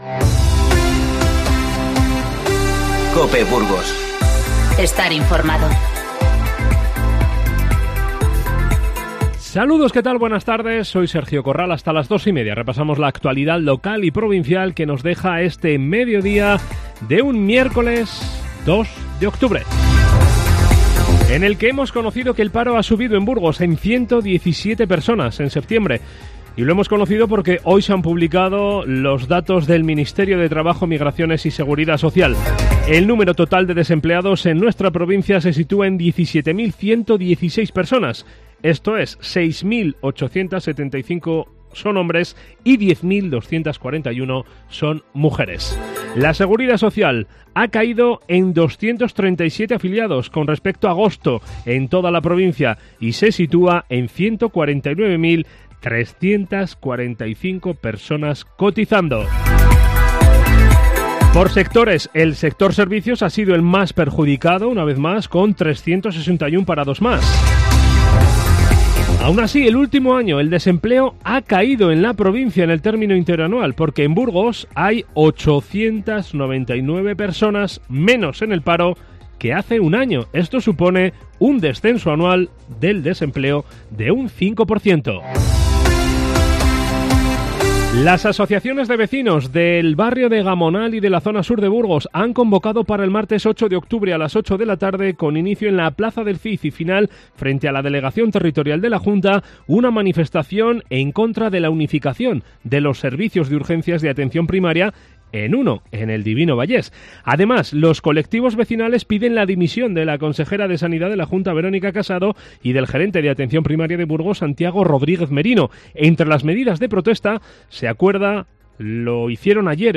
INFORMATIVO Mediodía 2-9-19.